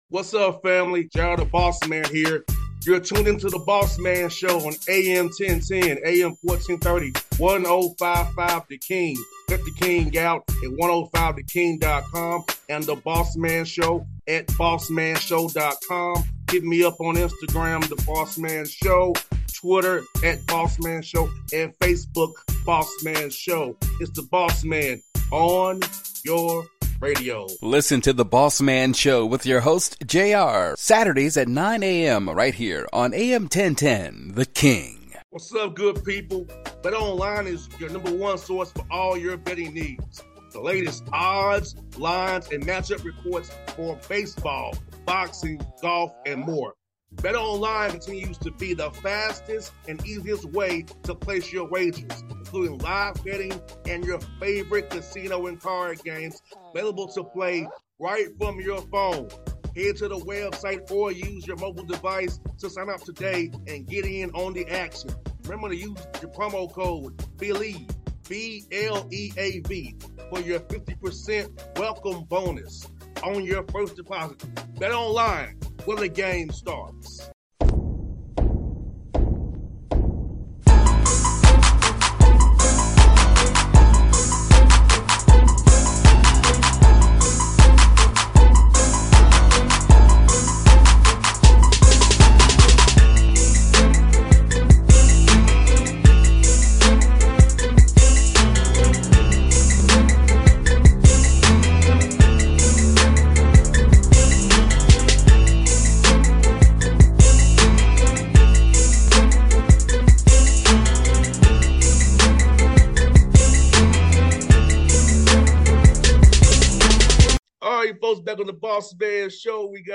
Damon Stoudamire Interview